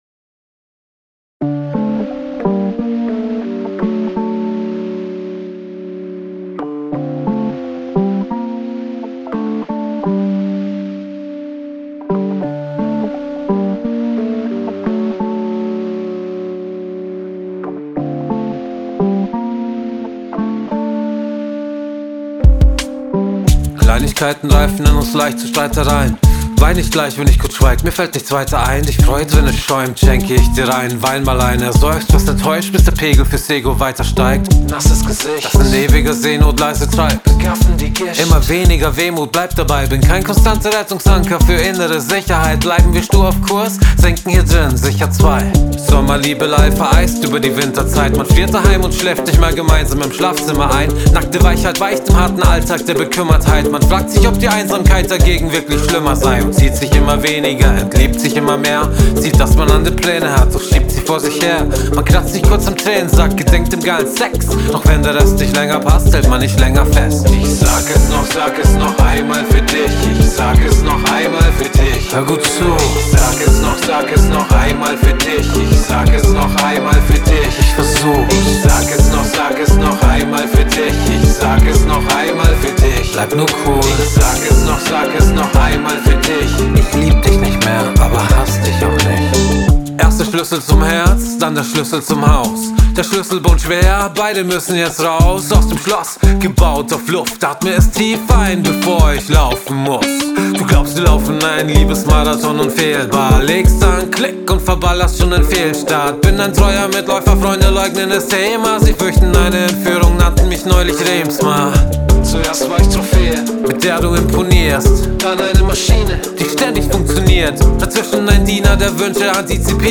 Rap
Snare zu leise im Vergleich zur Kick Stimme klingt etwas wie hinter einem Handtuch und durch diese Sättigung(?) reagiert es an manchen Stellen komisch, meist bei Frikativlauten Verzerrung an den Adlibs sehr gut; könnte dezent weniger, aber nur etwas (im Gesangspart des Outros würde ich die Zerre rausnehmen) Hi-Hats sehr laut Netter Reverb an den Gitarren Zum Vergrößern anklicken....